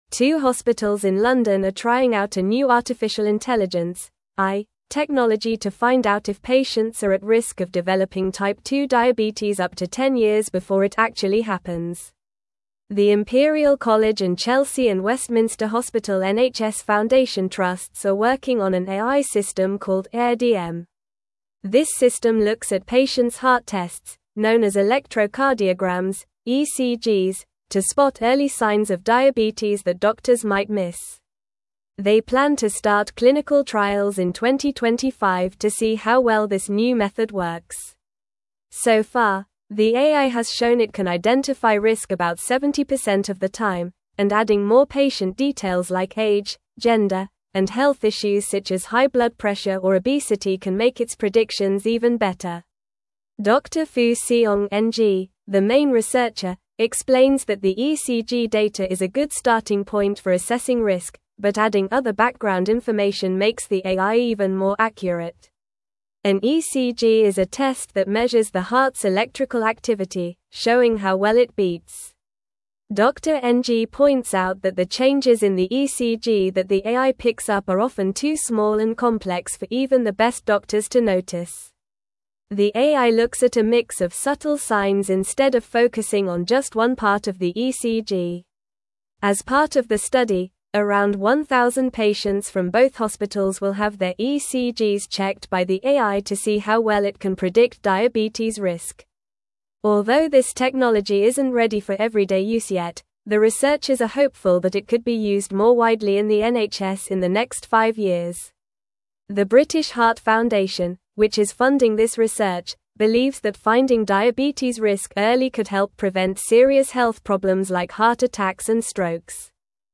Normal
English-Newsroom-Upper-Intermediate-NORMAL-Reading-AI-System-Predicts-Type-2-Diabetes-Risk-Early.mp3